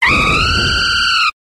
374Cry.wav